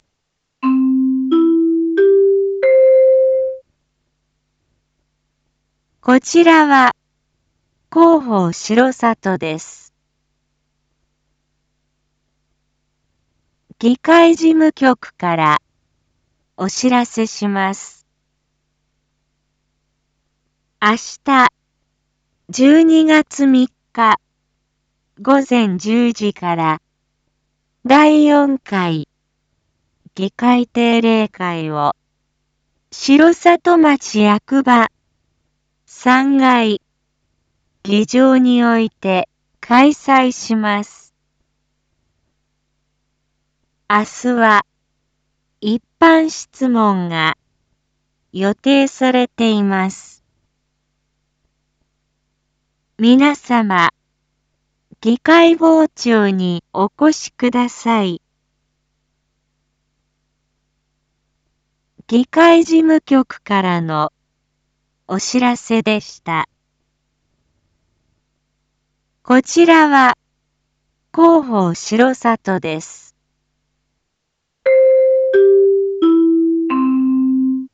一般放送情報
Back Home 一般放送情報 音声放送 再生 一般放送情報 登録日時：2025-12-02 19:01:19 タイトル：R7.12.3 第４回議会定例会③ インフォメーション：こちらは広報しろさとです。